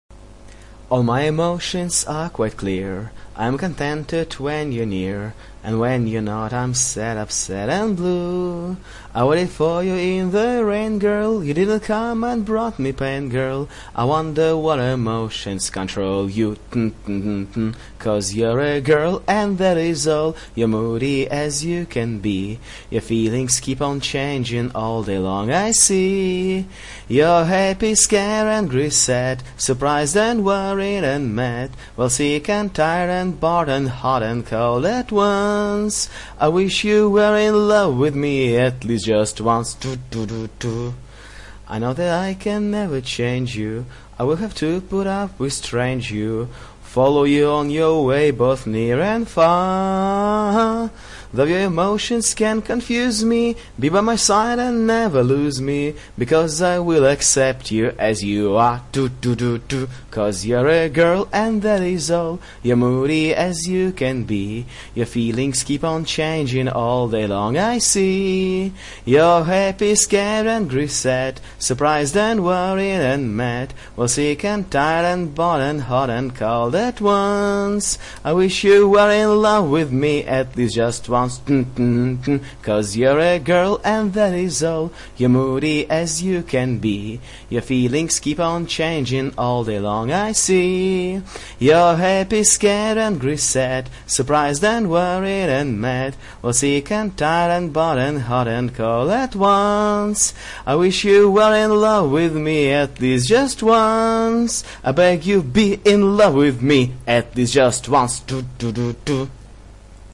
woman humming
描述：A woman humming a nondescript folksy tune. Alto voice/pitch. Recorded on an ipod touch.
标签： melody singing humming womanhumming womansvoice song